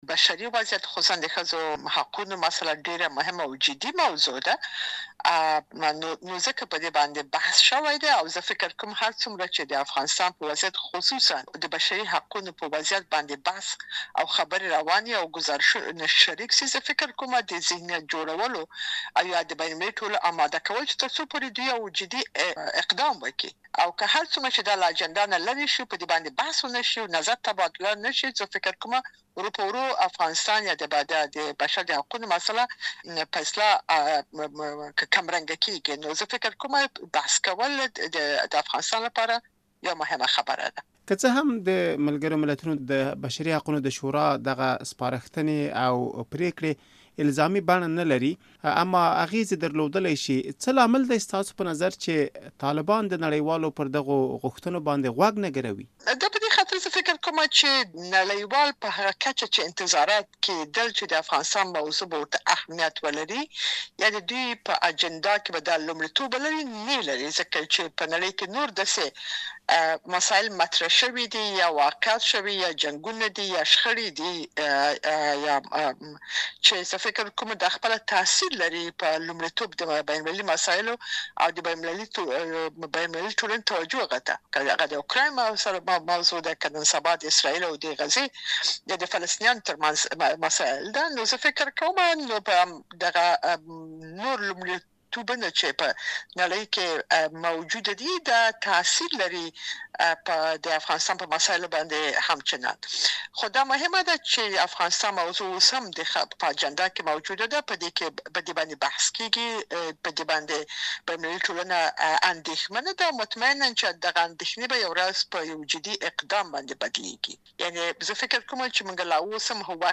مرکه
له شینکۍ کړوخېل سره مرکه